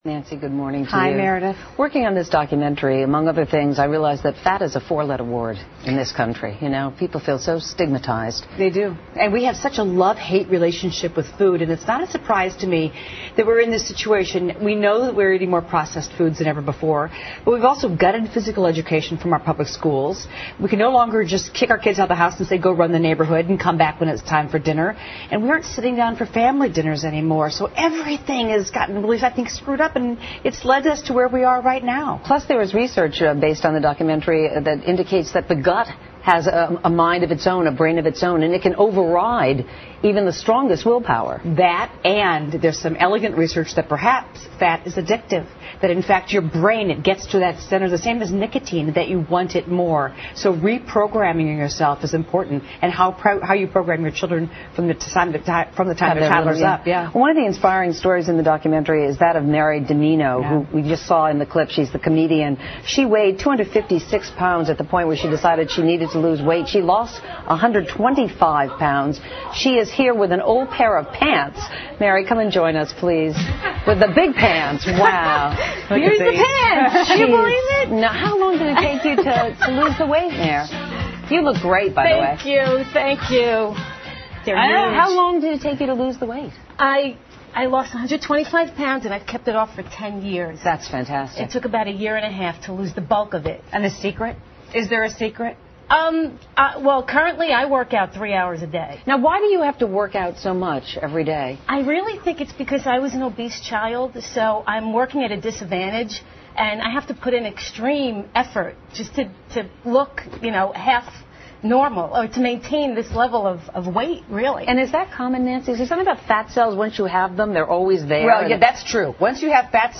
访谈录 Interview 2007-04-15&17, 揭开肥胖真相 听力文件下载—在线英语听力室